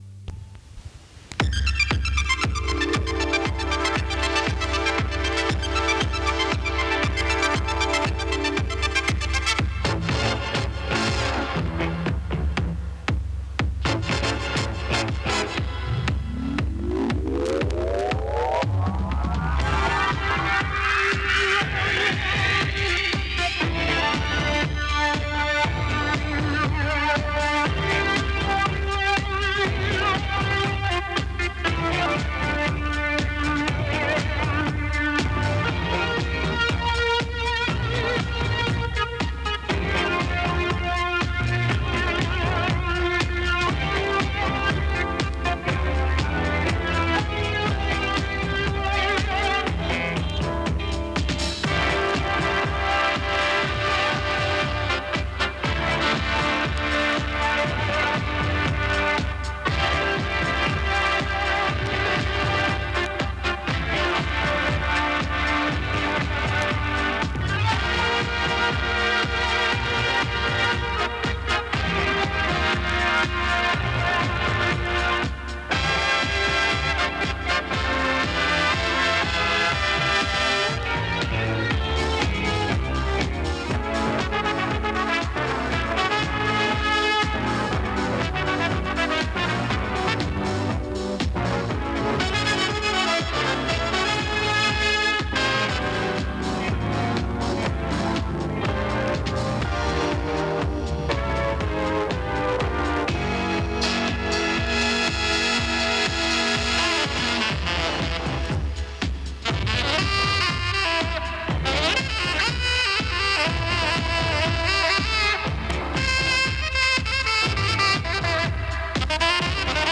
Друзья! Помогите, пожалуйста, определить автора и название мелодии
из радиоэфира